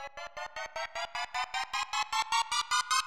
TI RISE.wav